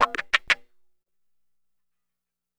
WAHKACHAK1-R.wav